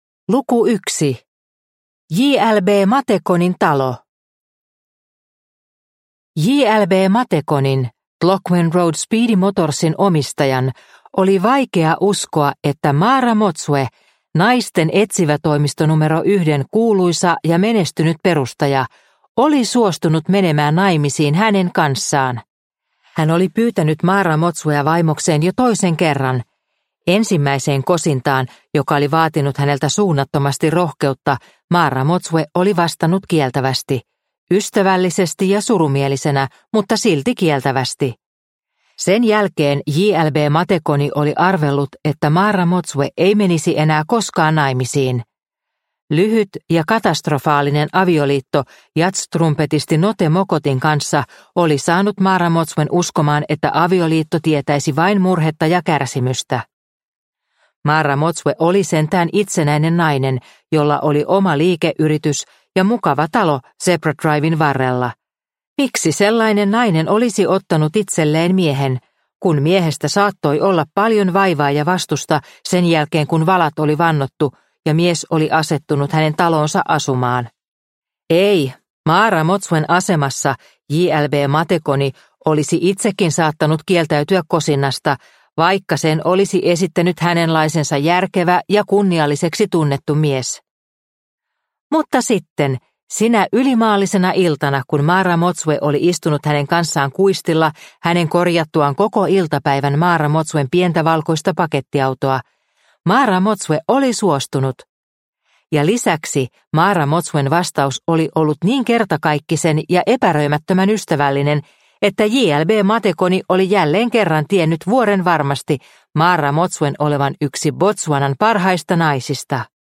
Kirahvin kyyneleet – Ljudbok – Laddas ner